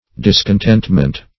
Discontentment \Dis`con*tent"ment\, n.